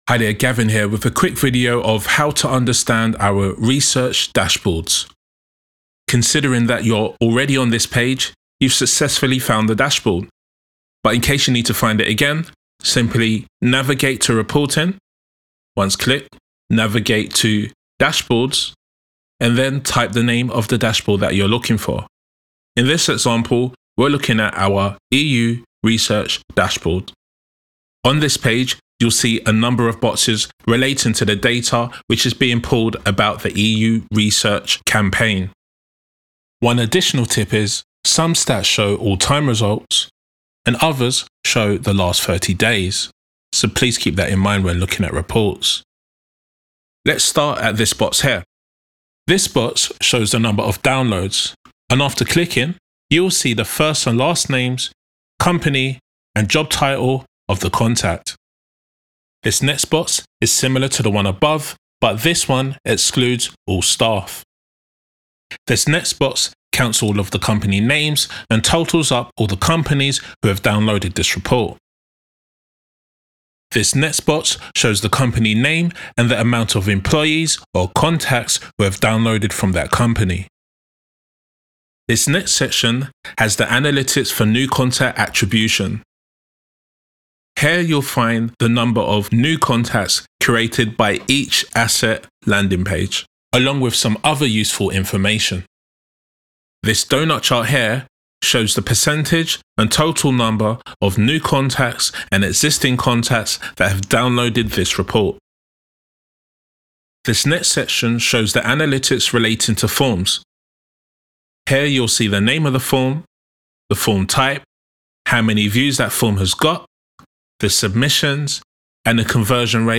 This is a voiceover example